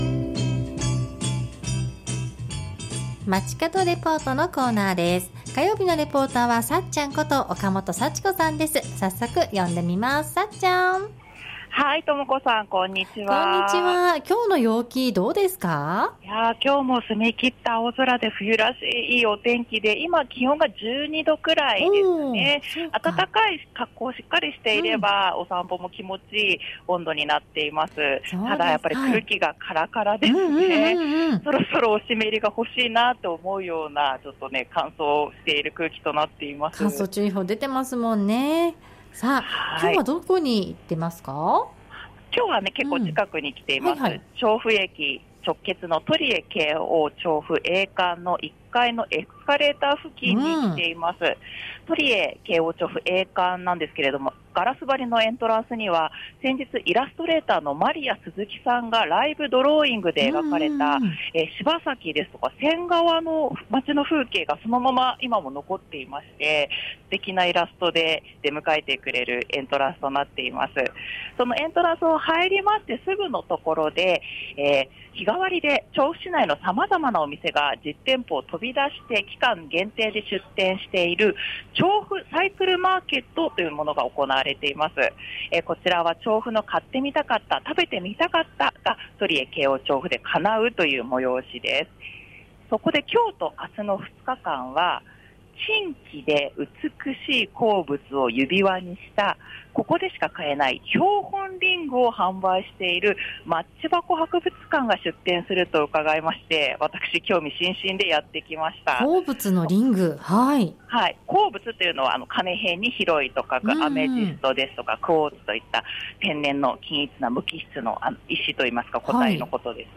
中継はトリエ京王調布A館１Fから「調布サイクルマーケット」に出店している「マッチ箱博物館」をご紹介しました。